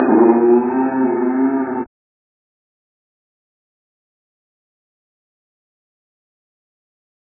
DMV3_Vox 13.wav